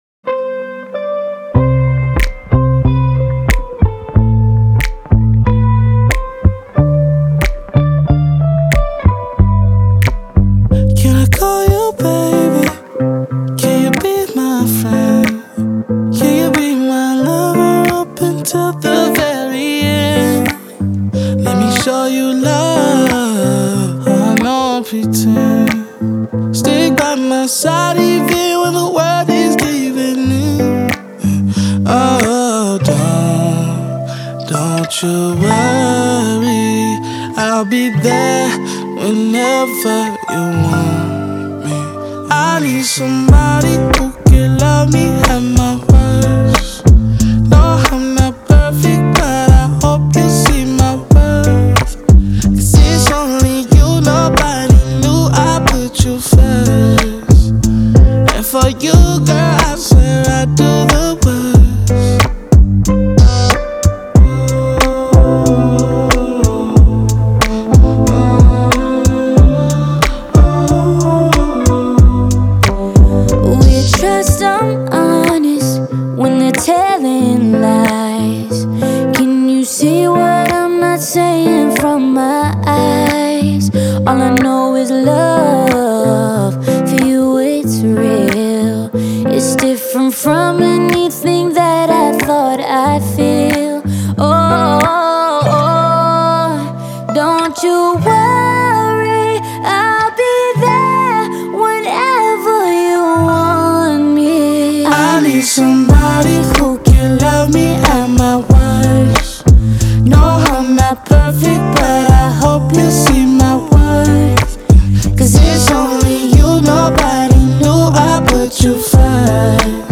нежная R&B баллада